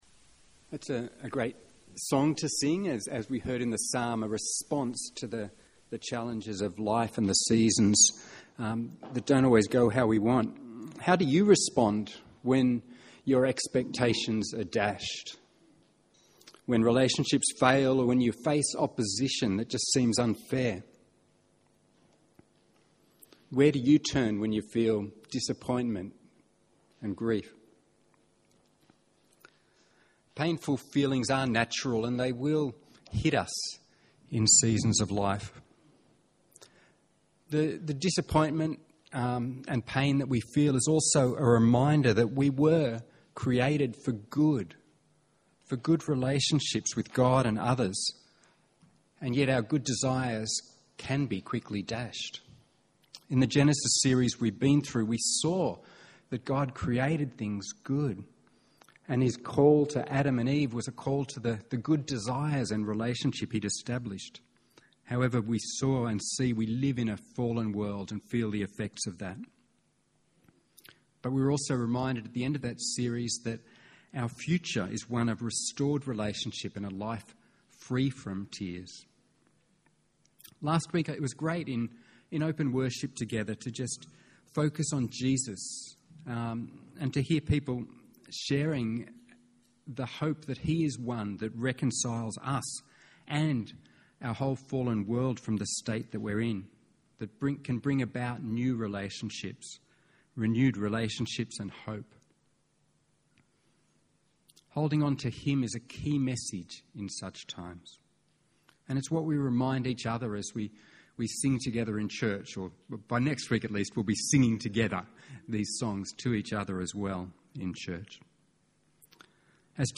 by admin | Nov 7, 2021 | A Psalm for Every Season, Sermons 2021